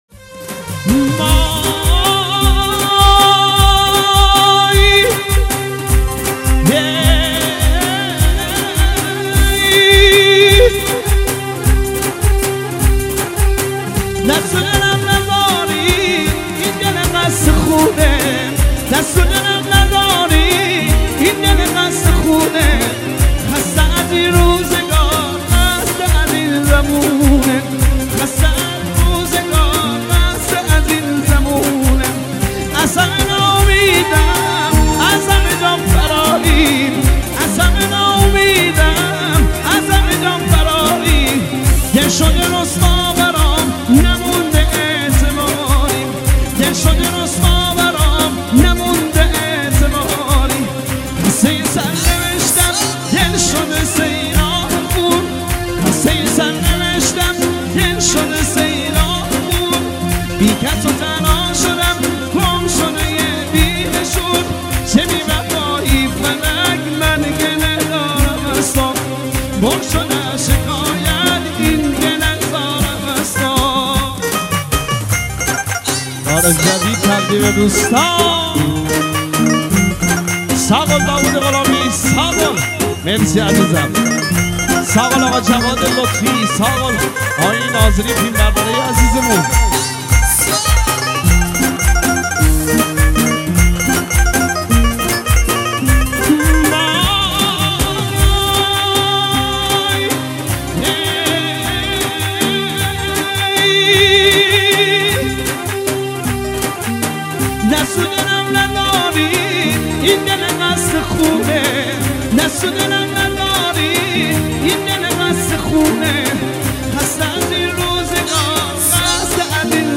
ارکستی شاد